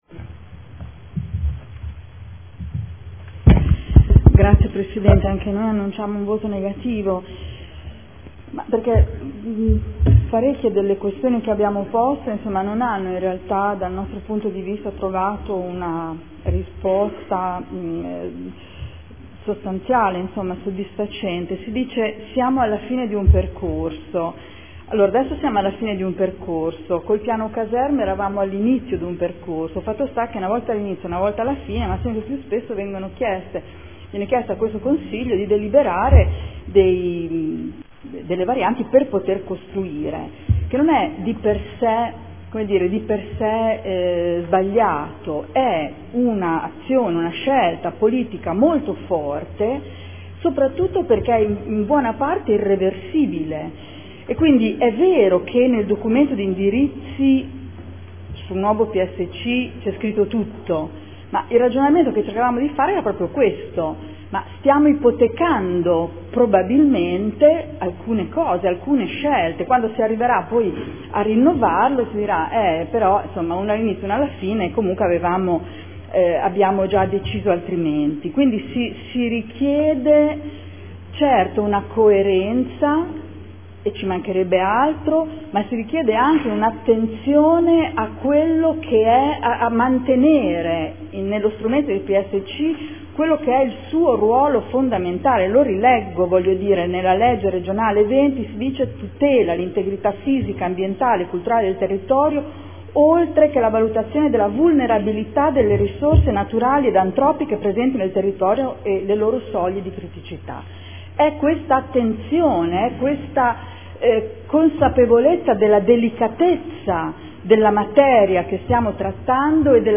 Seduta del 13/11/2014 Dichiarazione di voto.